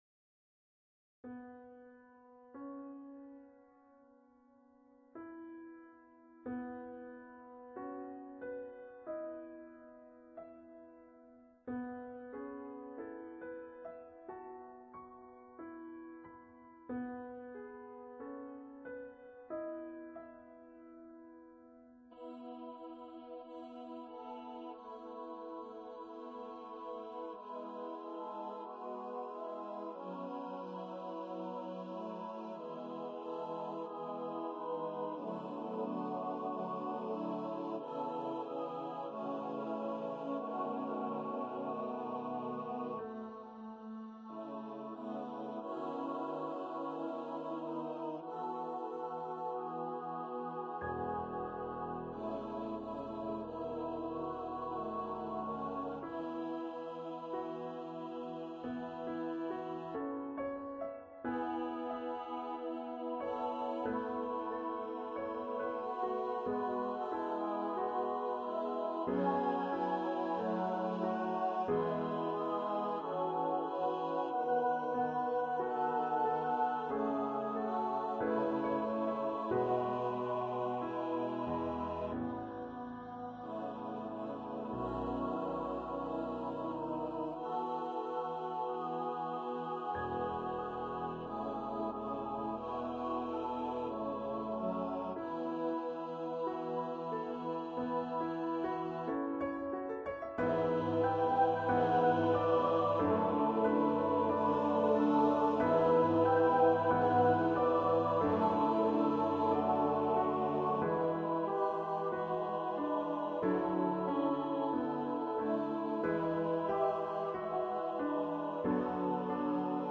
for mixed voice choir and piano
A carol for SATB choir and piano.
Choir - Mixed voices (SATB)